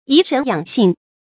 頤神養性 注音： ㄧˊ ㄕㄣˊ ㄧㄤˇ ㄒㄧㄥˋ 讀音讀法： 意思解釋： 謂保養精神元氣。